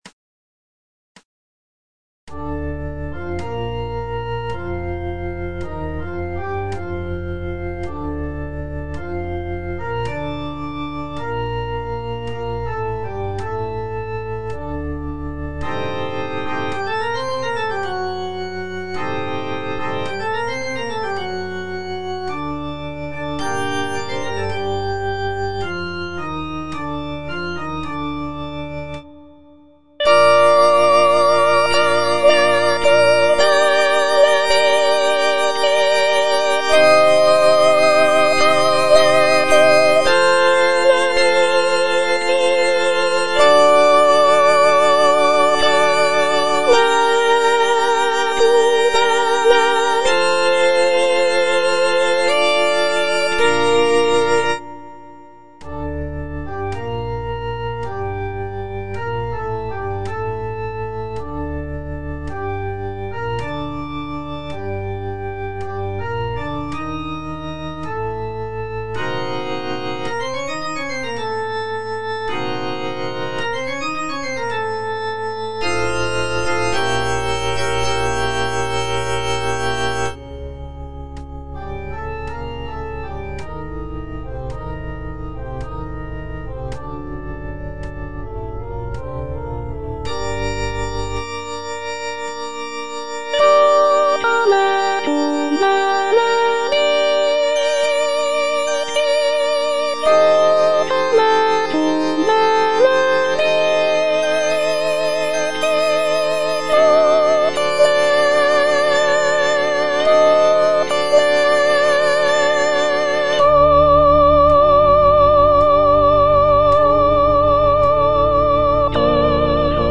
F. VON SUPPÈ - MISSA PRO DEFUNCTIS/REQUIEM Confutatis (soprano I) (Voice with metronome) Ads stop: auto-stop